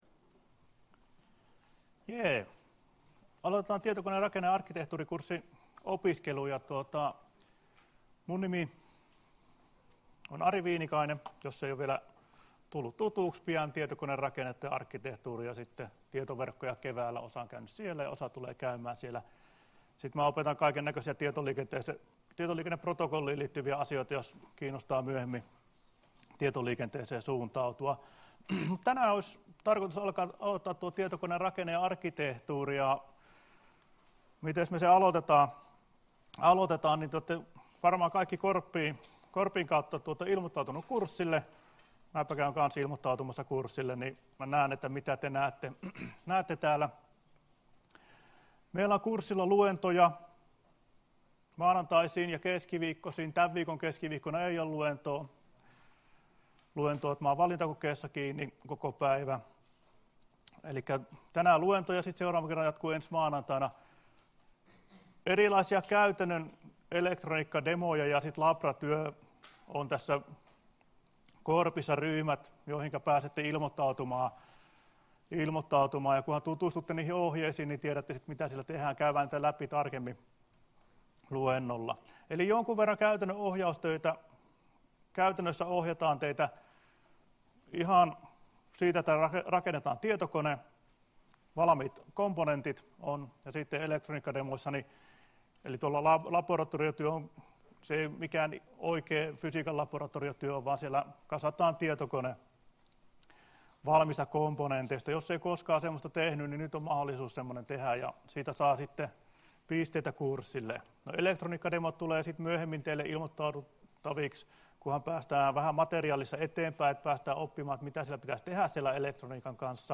Luento 23.10.2017 — Moniviestin